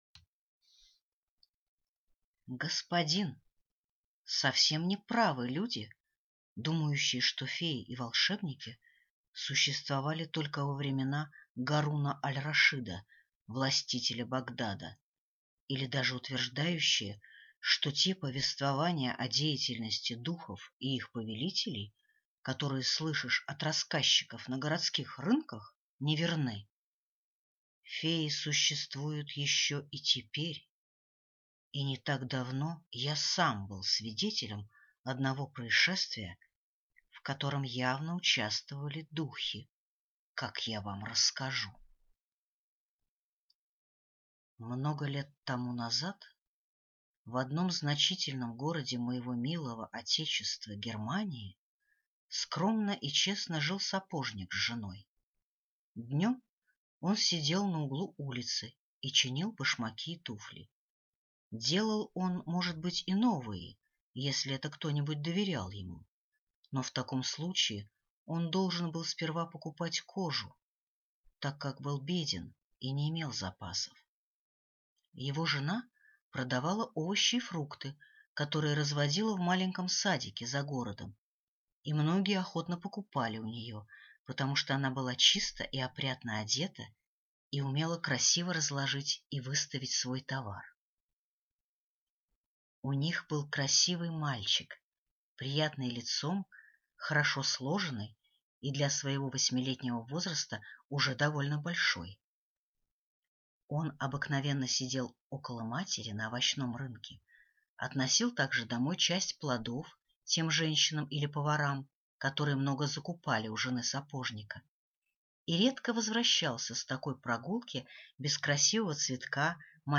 Аудиокнига Карлик Hoc | Библиотека аудиокниг